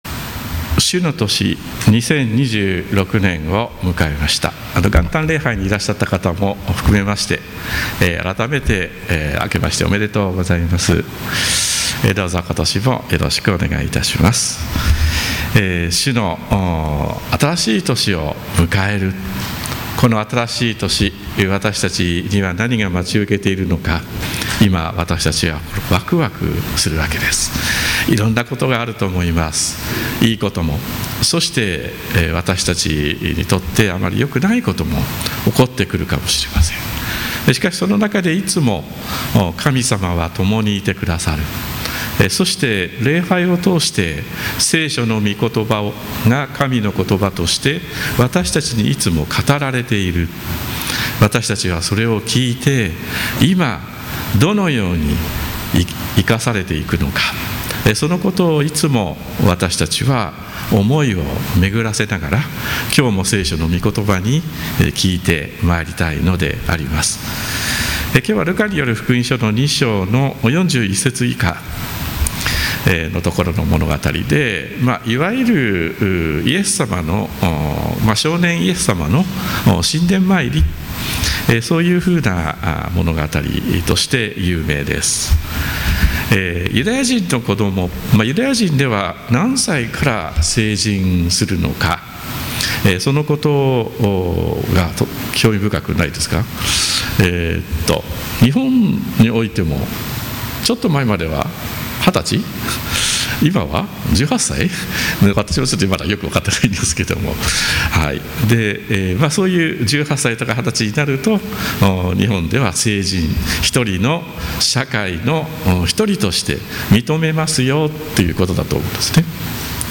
復活節第3主日礼拝 説教
一部音声の乱れなどがあります。